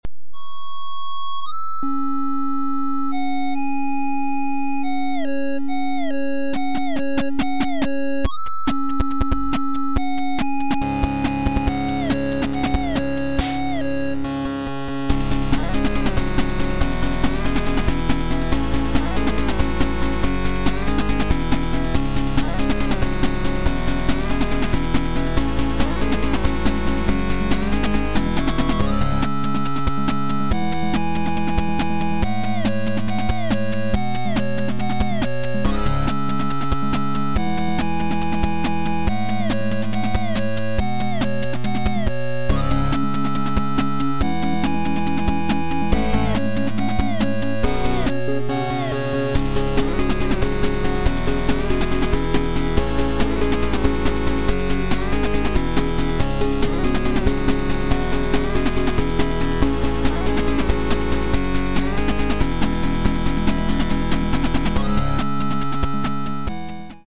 8Bit Cover
#8bitmusic